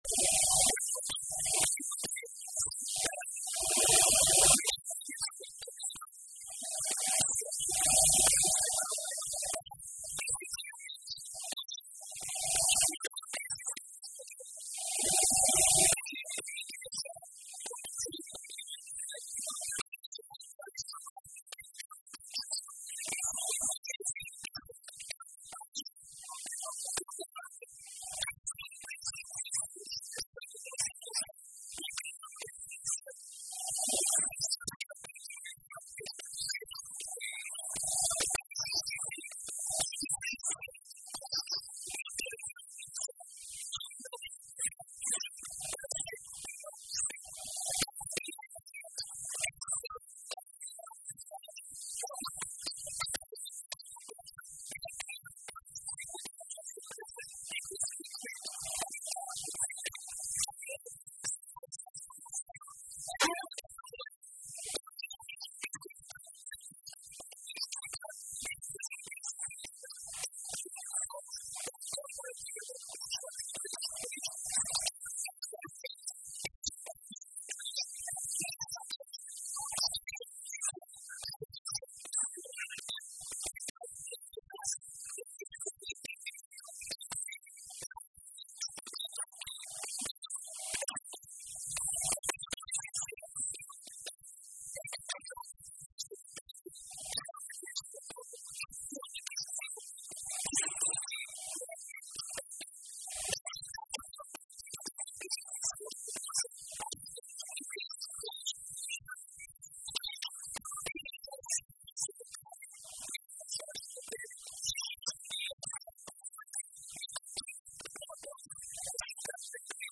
O Vice-Presidente do Governo destacou hoje, na Assembleia Legislativa, o património da governação socialista no que respeita à diferenciação de apoios às famílias, aos trabalhadores e aos pensionistas nos Açores em relação ao resto do país.